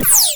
Index of /90_sSampleCDs/E-MU Producer Series Vol. 3 – Hollywood Sound Effects/Science Fiction/Columns
HI BURST R05.wav